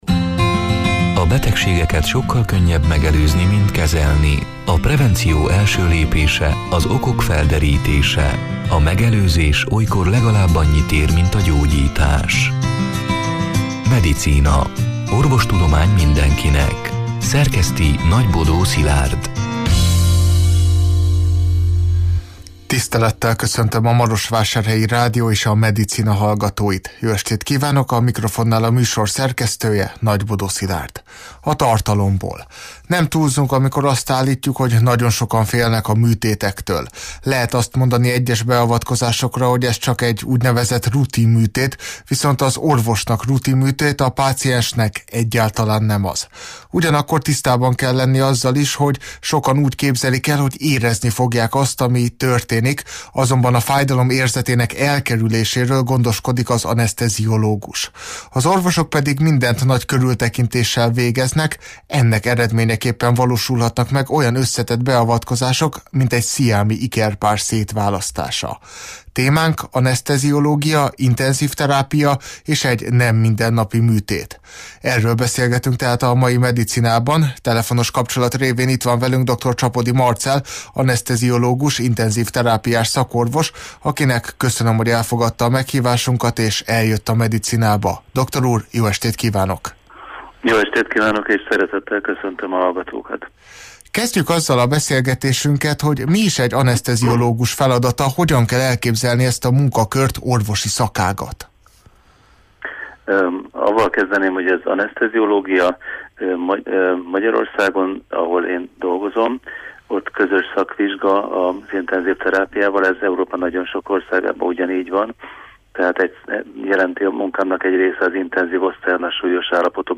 A Marosvásárhelyi Rádió Medicina (elhangzott: 2024. május 8-án, szerdán este nyolc órától élőben) c. műsorának hanganyaga: Nem túlzunk amikor azt állítjuk, hogy nagyon sokan félnek a műtétektől.